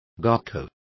Complete with pronunciation of the translation of gauchos.